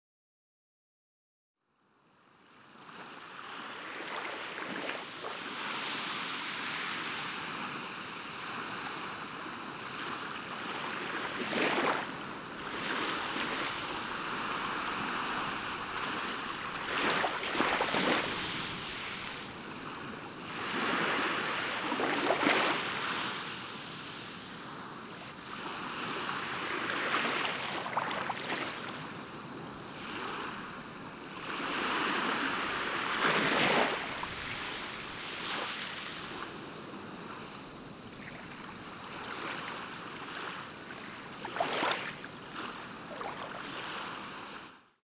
波の音（※４）